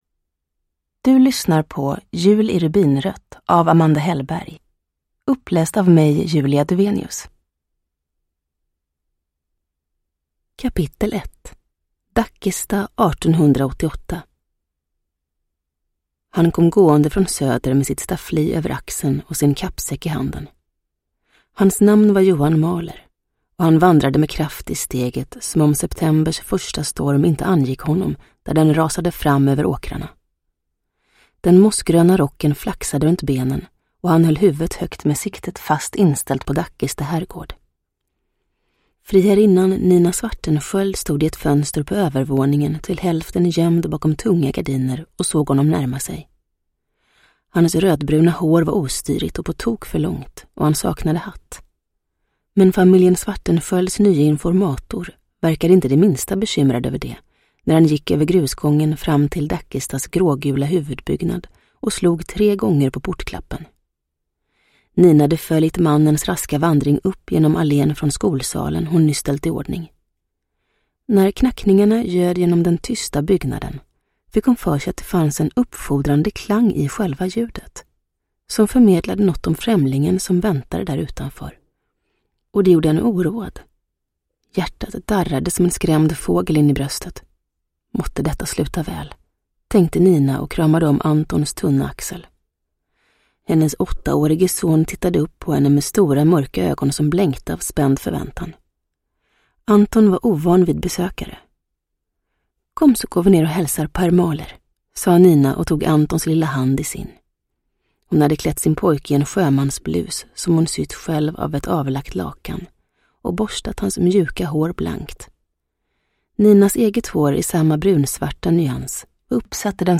Uppläsare: Julia Dufvenius
Ljudbok